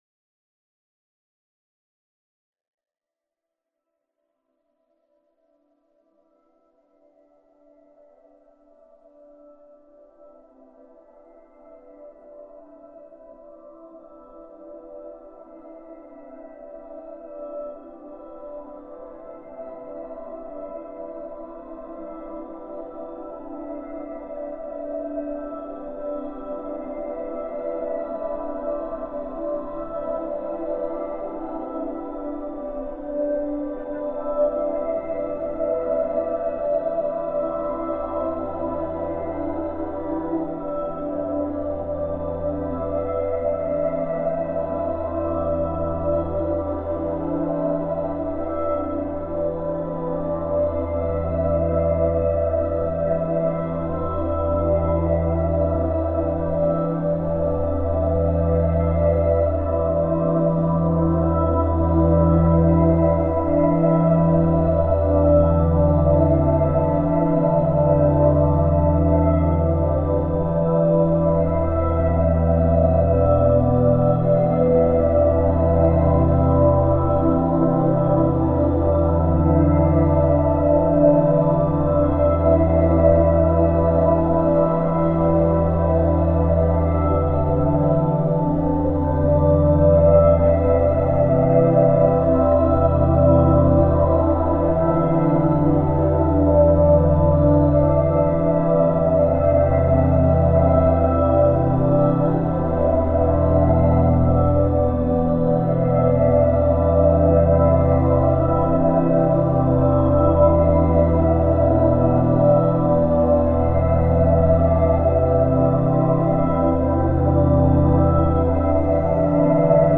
File under: Ambient / Drone Music